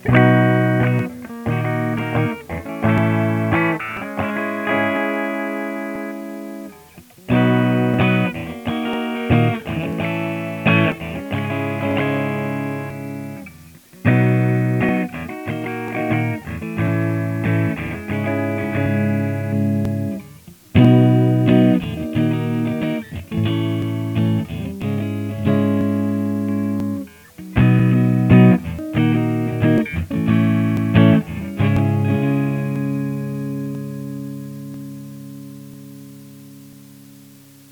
bon, c'est pas grand chose, juste quatre accords en son clair et avec les égalisations à "zéro", sur les 5 positions du sélecteur (positions standard d'une strat HSH sans split), en partant du micro chevalet jusqu'au micro manche.
:dance: :dance: enfin elle a poussé ses premiers cris :dance: :dance: